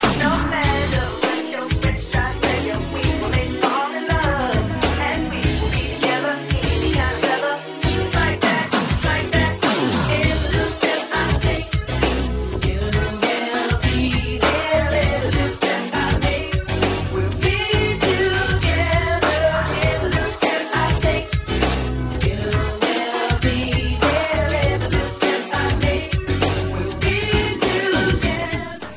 background vocals and keyboards